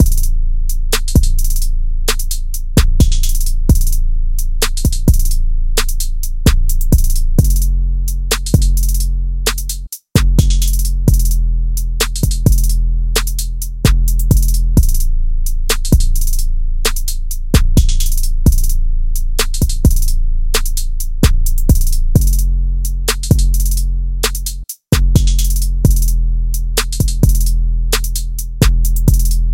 速度 陷阱鼓循环
描述：楼梯鼓循环
标签： 130 bpm Trap Loops Drum Loops 4.97 MB wav Key : Unknown
声道立体声